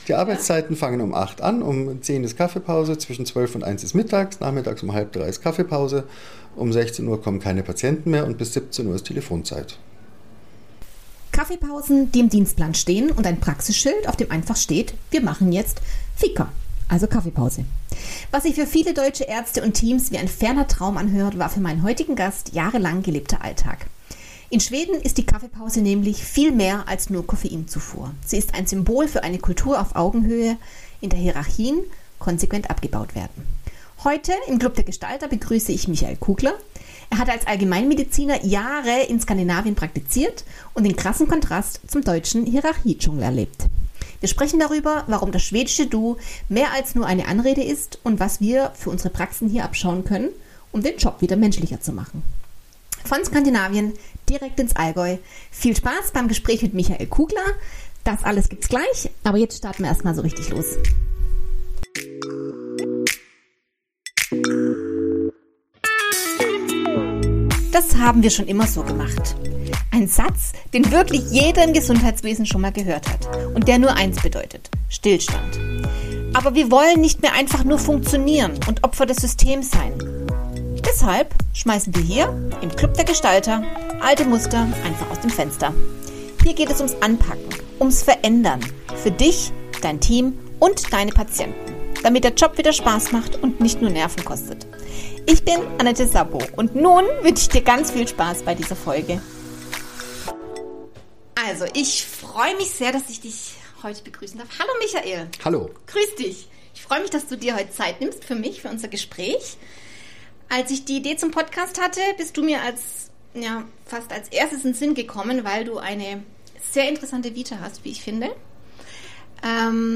[Interview] Hej Schweden: Warum 10 Uhr morgens die Praxis stillsteht ~ Das haben wir schon IMMER so gemacht!